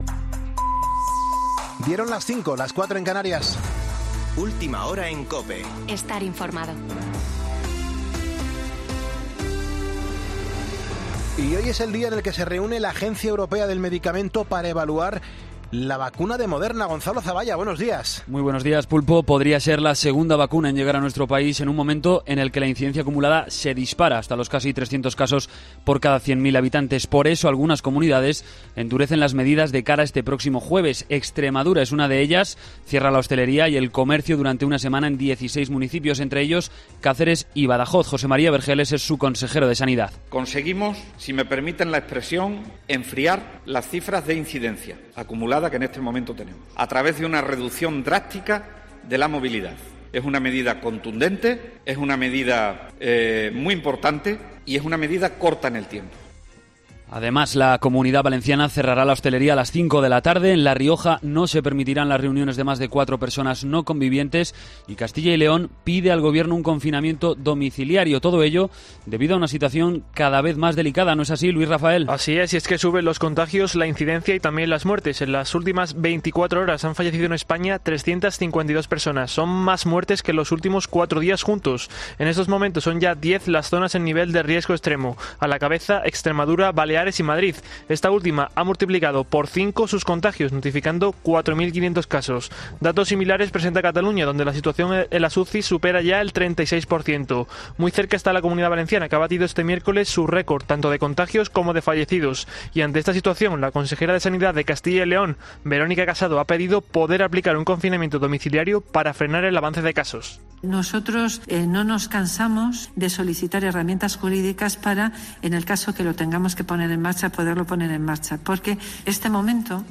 Boletín de noticias COPE del 6 de enero de 2020 a las 05.00 horas